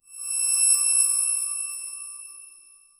A satisfying sci-fi chime with a soft magnetic pull — like data being locked into a high-tech system, followed by a gentle energy pulse confirming success in a clean, advanced interface.
a-satisfying-sci-fi-chime-r7cqn66k.wav